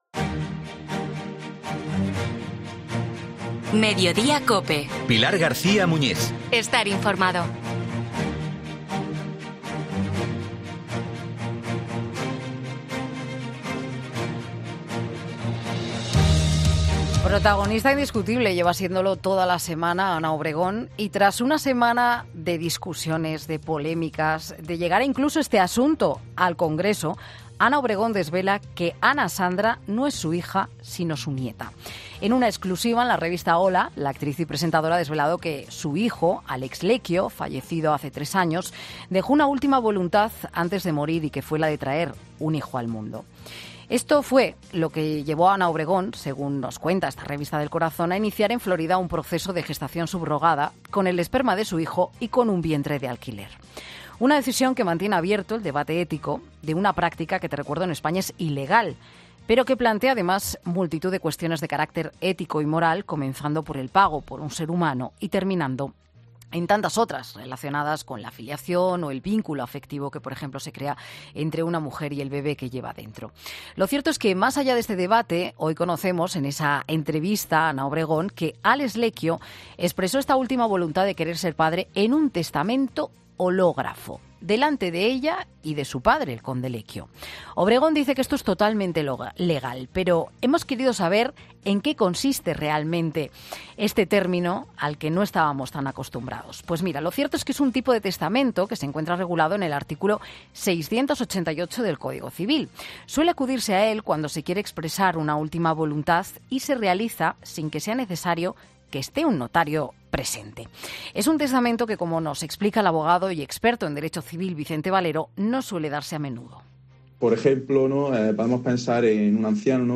'Mediodía COPE', con la ayuda de un abogado experto en Derecho Civil, explica en qué consiste y cuándo se puede aplicar este tipo de testamento regulado en el Código Civil